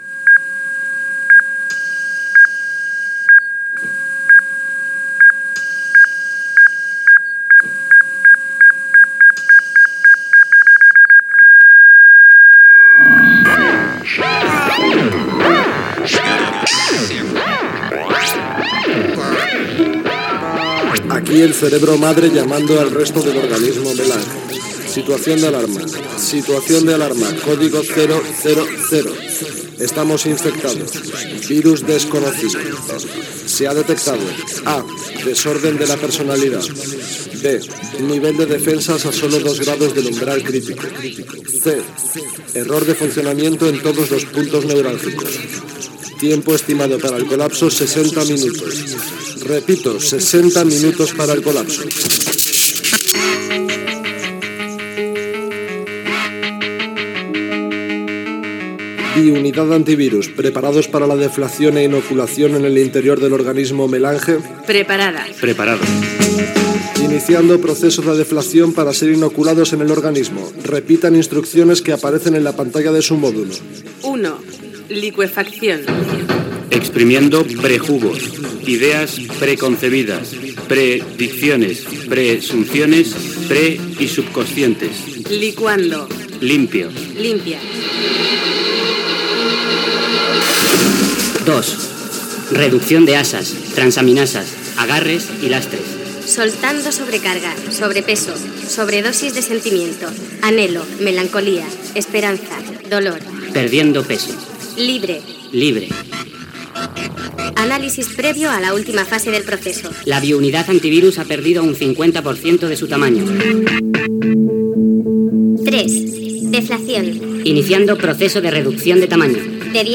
Viatge a l'interior d'un organisme Gènere radiofònic Ficció